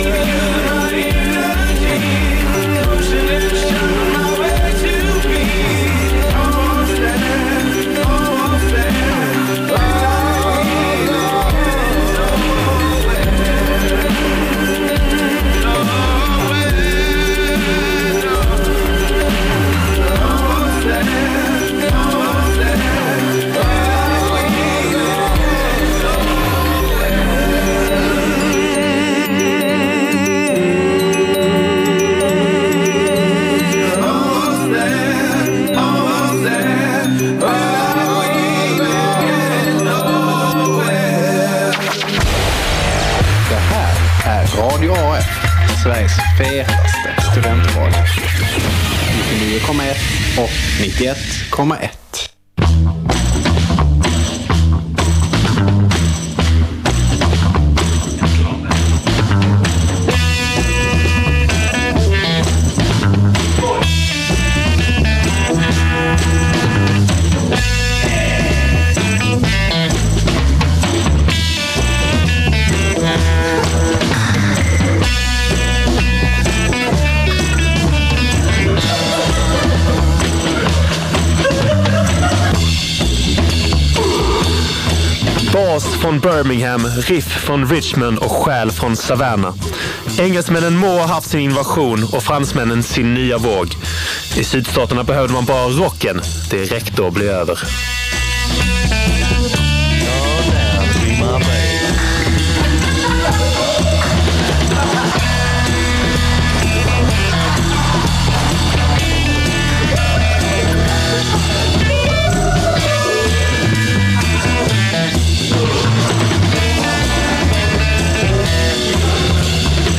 Idag styr Reverb sin truck i Mississippis färdriktning och smakar på sydstatsrocken i all dess råa prakt.